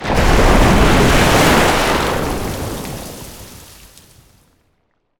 water_spell_wave_crash_01.wav